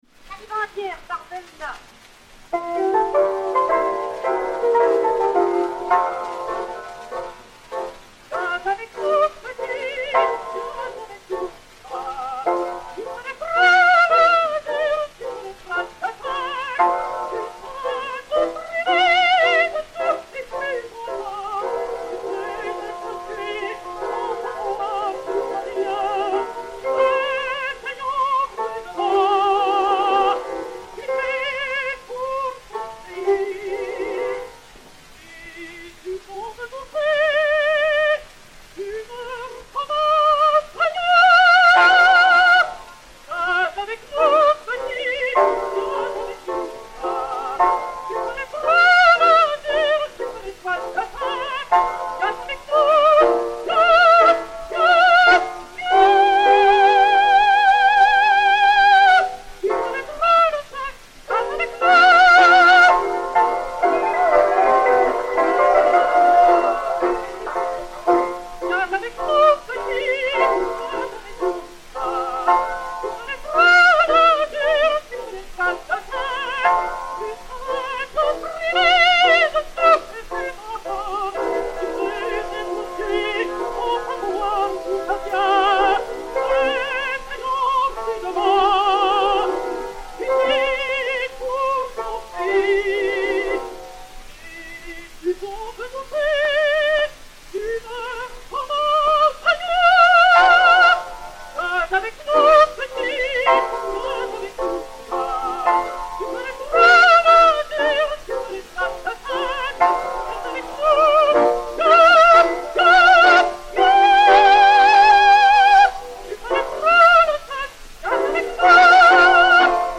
Marie Delna (Marion, créatrice) et Piano
Pathé saphir 90 tours n° 3504, enr. en 1903/1904